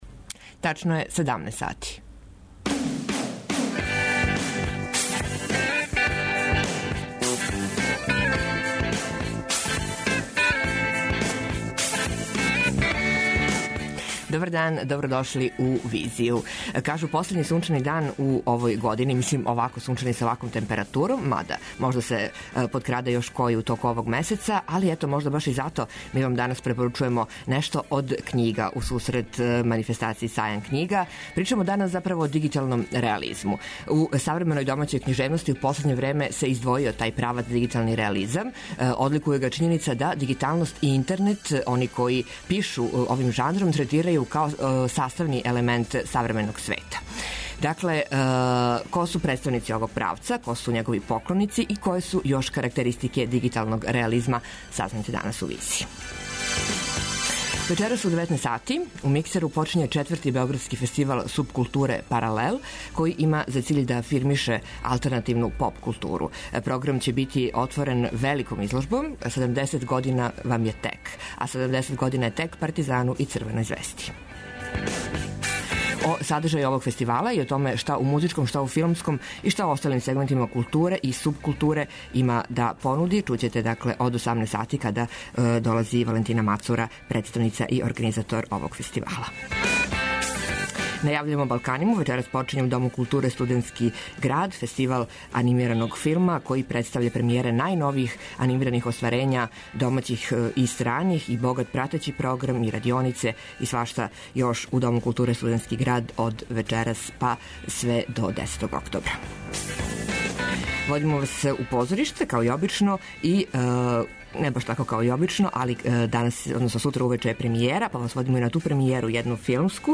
Београд 202 Социо-културолошки магазин, који прати савремене друштвене феномене.